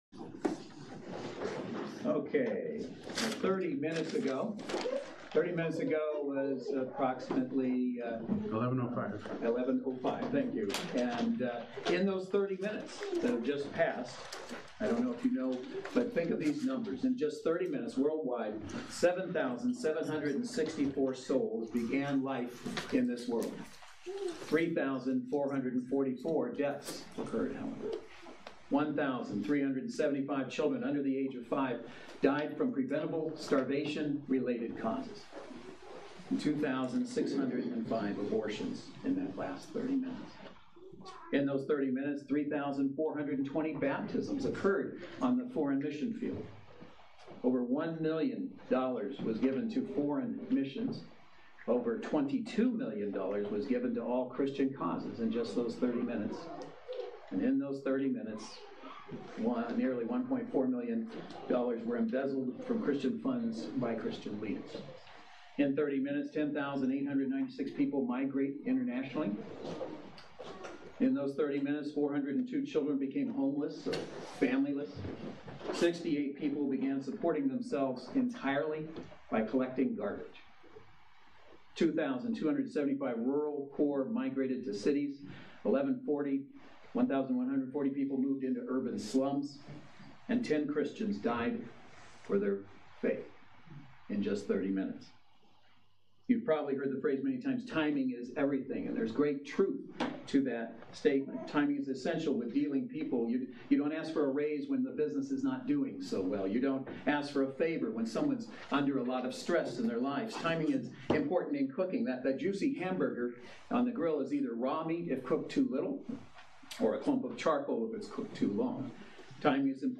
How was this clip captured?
Ecclesiastes Service Type: Saturday Worship Service Speaker